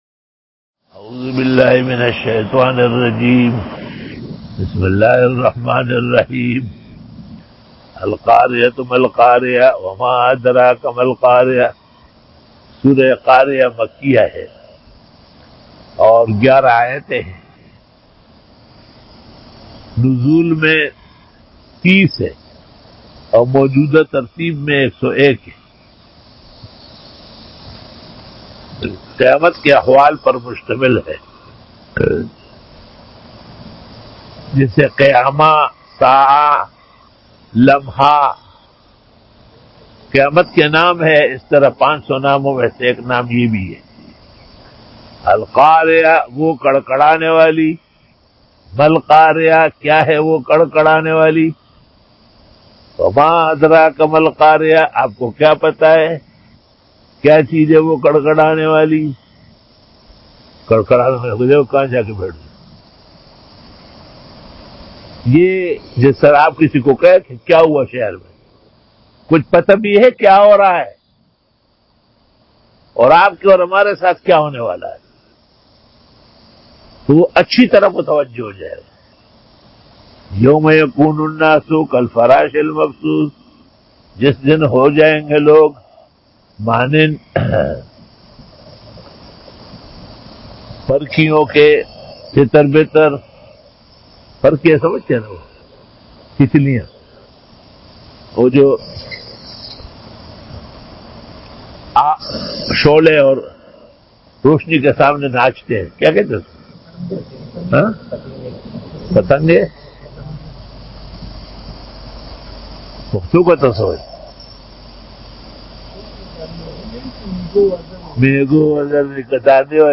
Bayan by